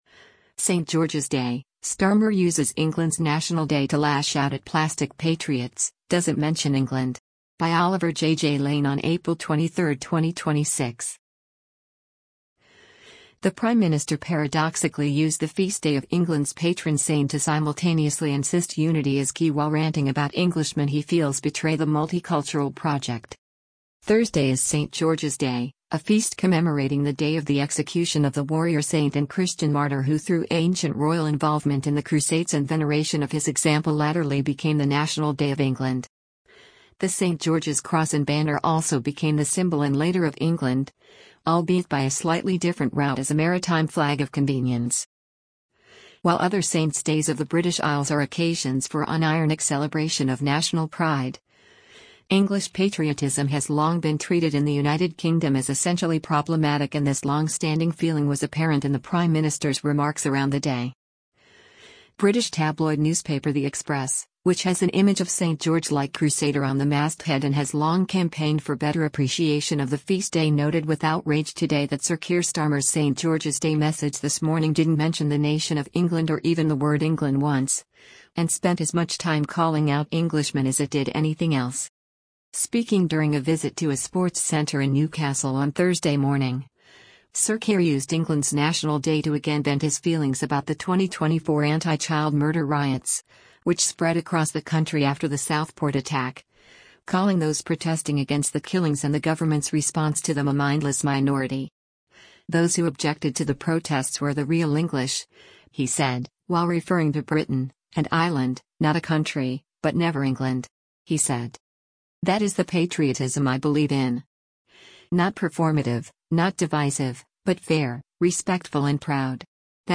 Prime Minister Sir Keir Starmer delivers a speech during a reception marking St George&#03